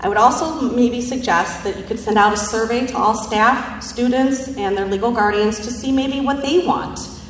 An audience at the Ottawa High School Board meeting tonight asked that there be no mask requirement.